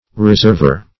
reserver - definition of reserver - synonyms, pronunciation, spelling from Free Dictionary
Reserver \Re*serv"er\ (r[-e]*z[~e]rv"[~e]r), n.